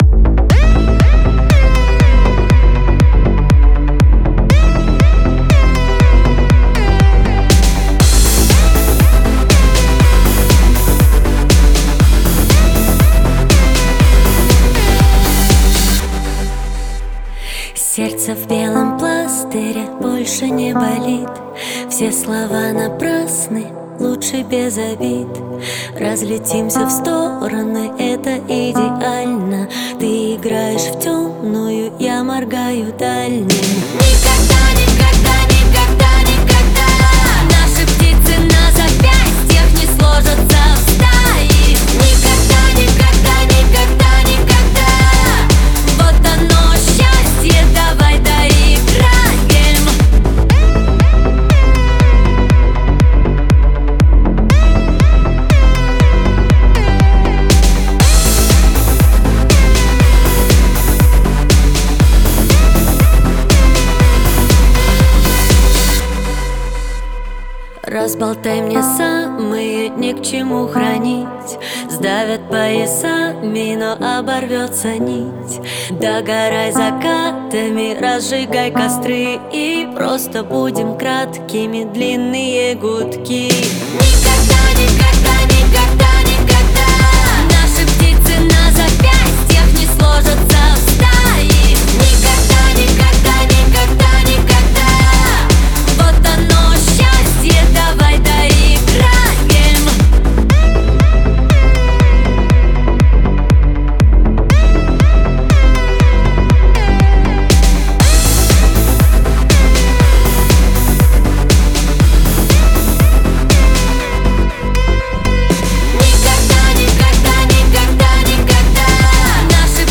эмоциональная поп-баллада